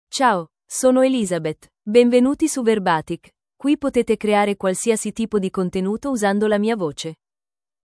Elizabeth — Female Italian (Italy) AI Voice | TTS, Voice Cloning & Video | Verbatik AI
Elizabeth is a female AI voice for Italian (Italy).
Voice sample
Listen to Elizabeth's female Italian voice.
Female
Elizabeth delivers clear pronunciation with authentic Italy Italian intonation, making your content sound professionally produced.